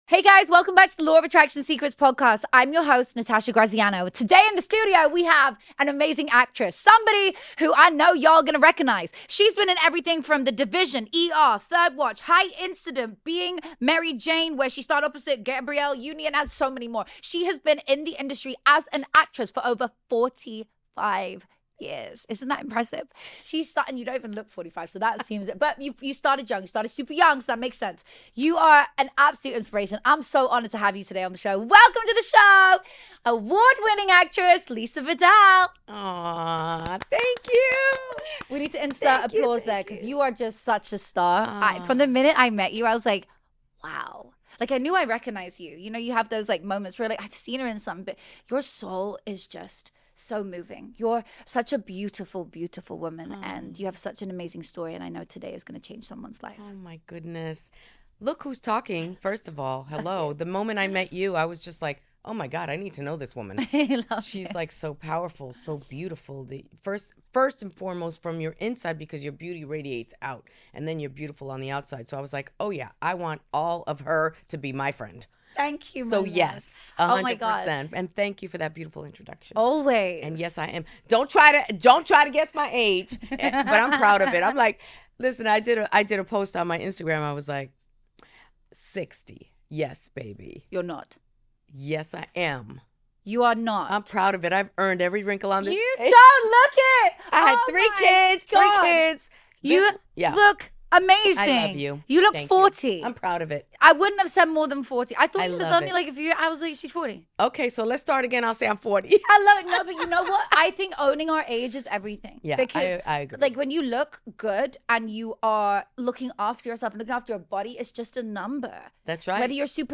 Hey angels ✨ This week on Law of Attraction Secrets, I’m joined by the radiant Lisa Vidal — actress, truth-teller, and powerful example of what happens when you choose healing over hiding.